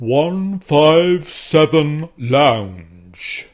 Tag: 论坛 幽默 男性 要求 说话 声音语音